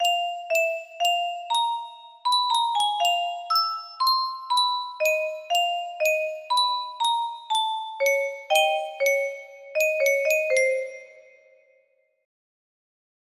Unknown Artist - Untitledasdasdasdasd music box melody